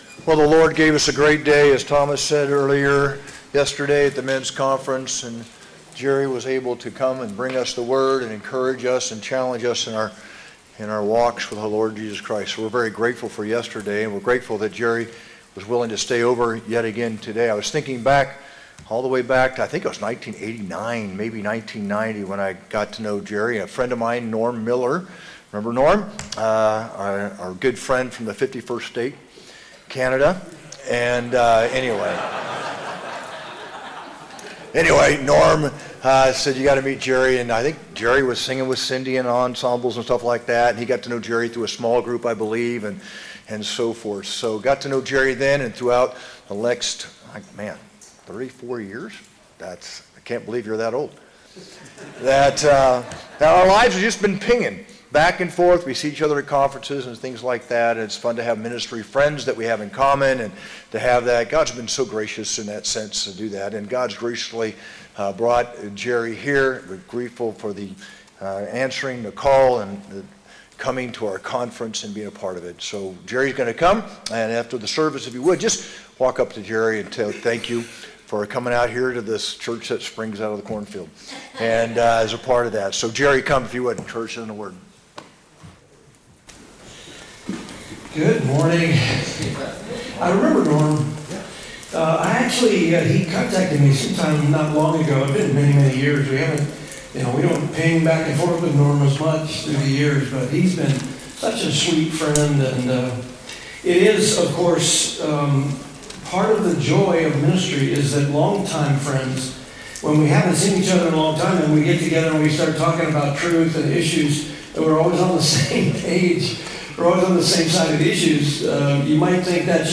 Guest Speaker
sermon-2-23-25.mp3